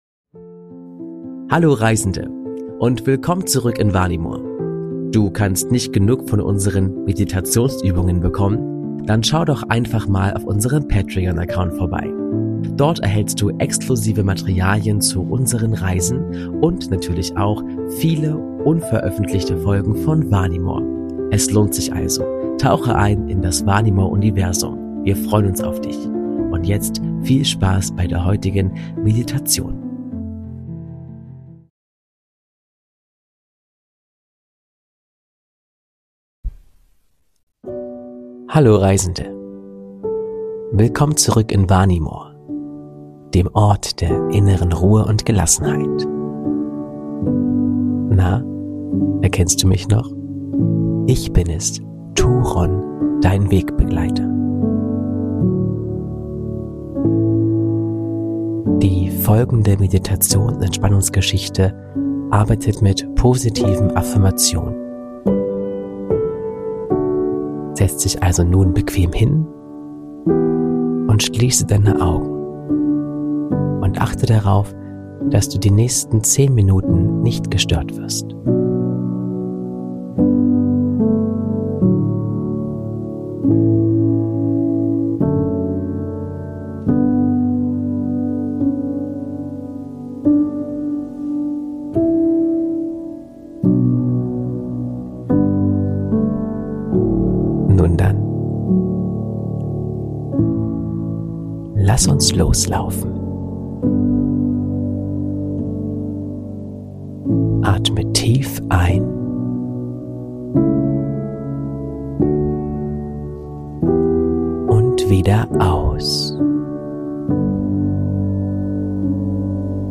In dieser Meditation arbeiten wir mit Affirmationen zum Thema Geduld im Alltag. Lass dich von der ruhigen Atmosphäre des Sees von Valoria inspirieren und finde deine innere Balance.